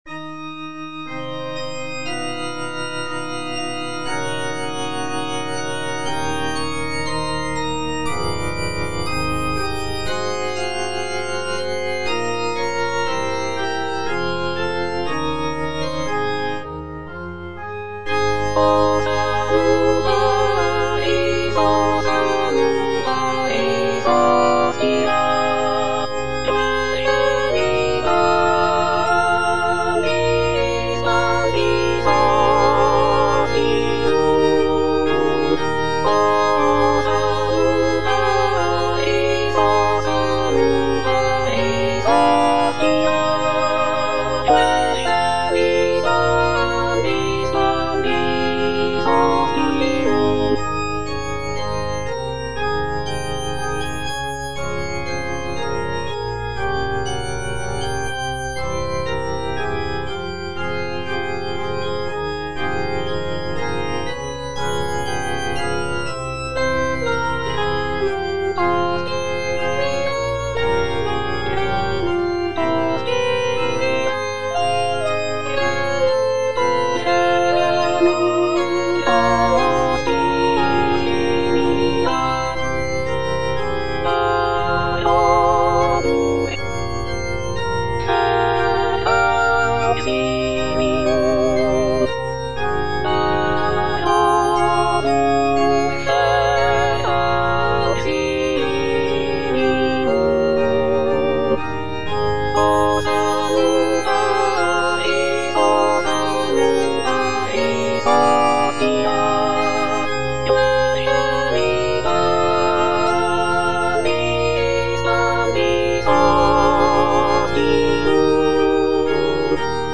The composition is a short and simple mass setting, featuring delicate melodies and lush harmonies.
G. FAURÉ, A. MESSAGER - MESSE DES PÊCHEURS DE VILLERVILLE O salutaris (All voices) Ads stop: auto-stop